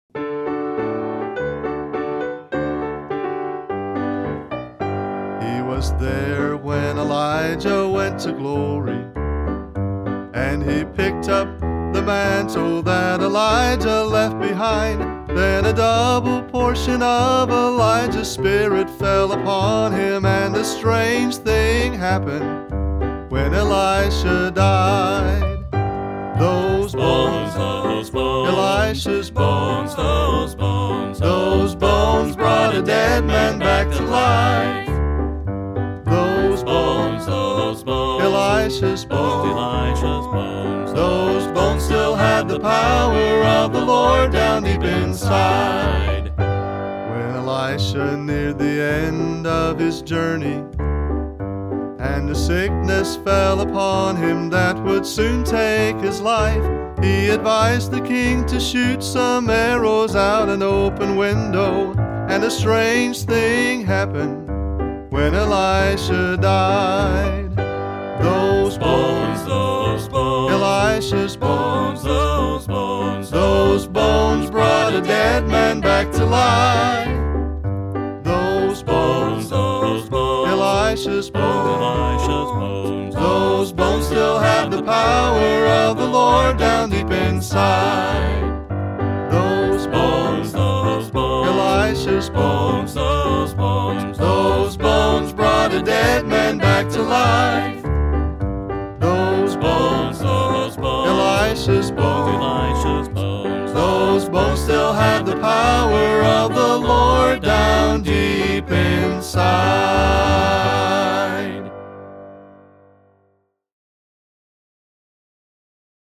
is a quartet style song.